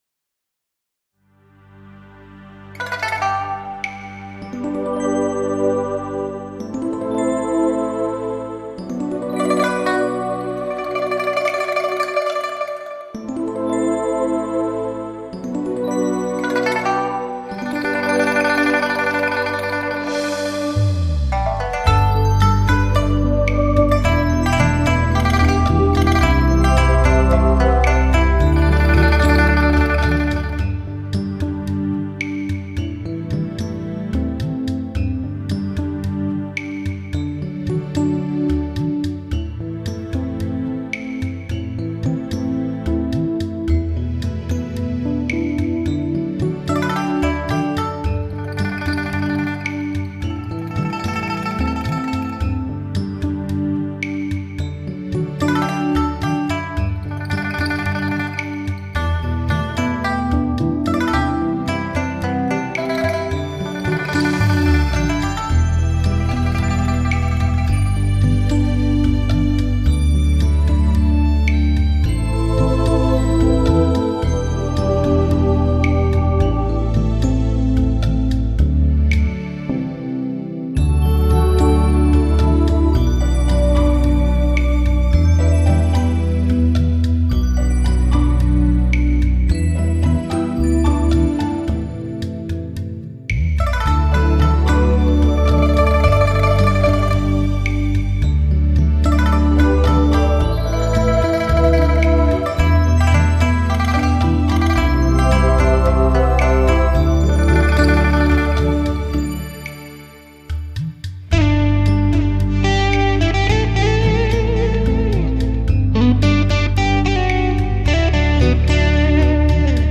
无 调式 : G 曲类